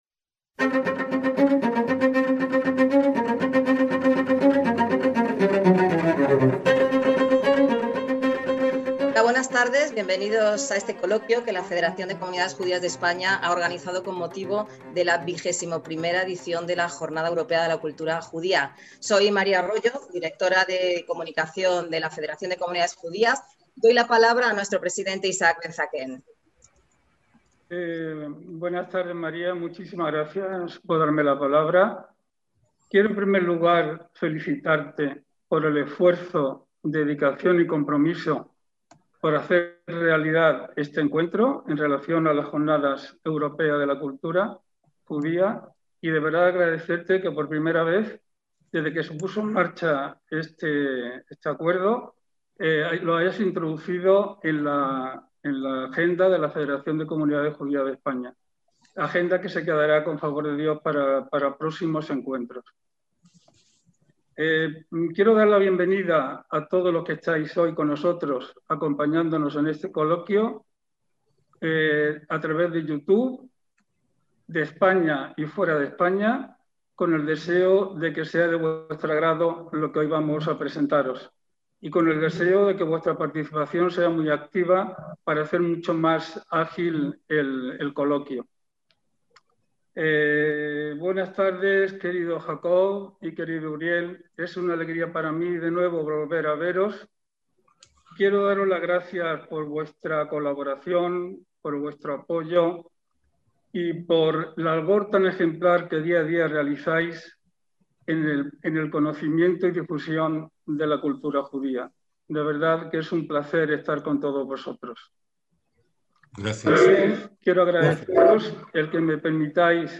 ACTOS "EN DIRECTO"